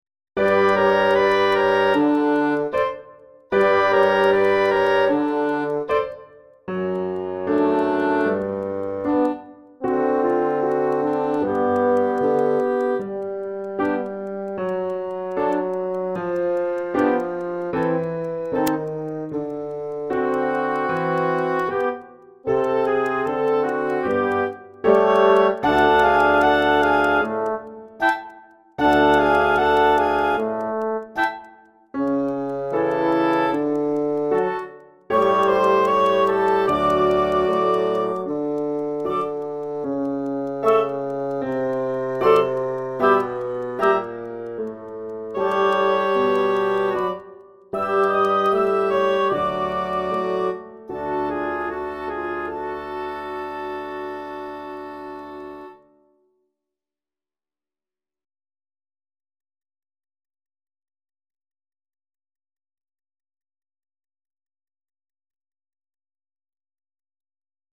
An episode by Simon Balle Music